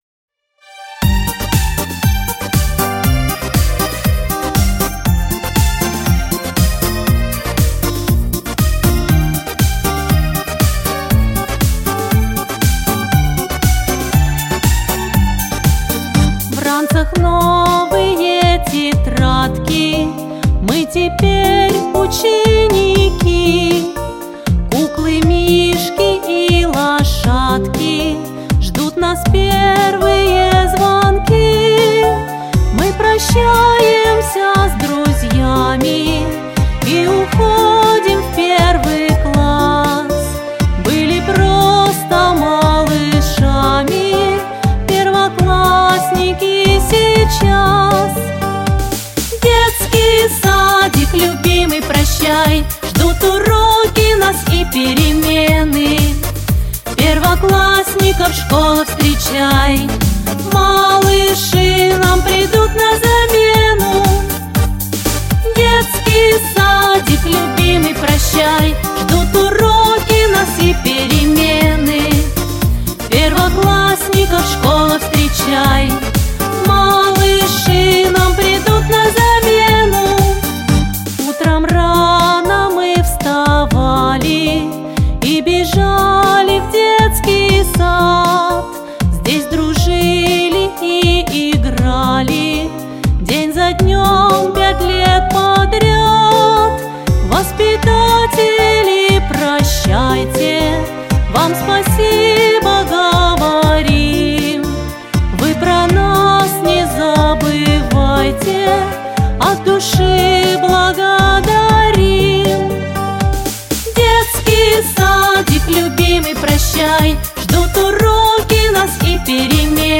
Детские песни и музыка